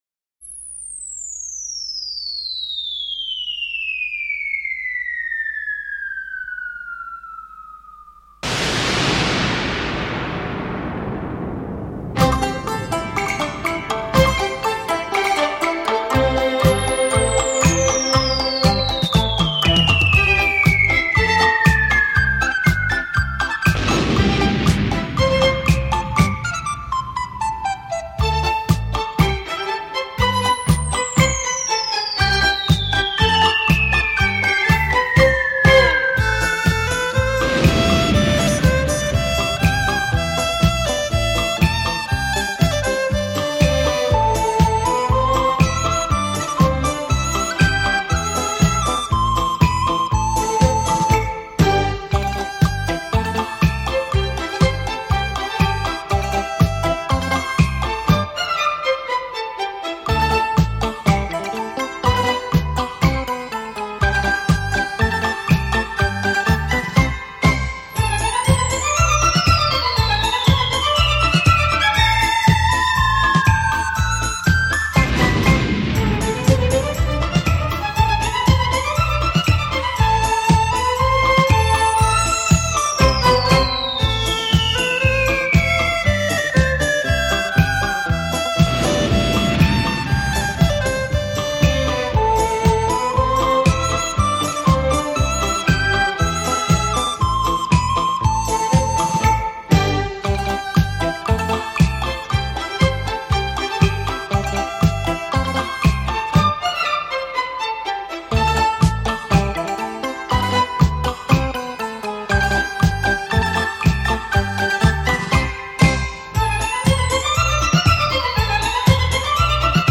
华乐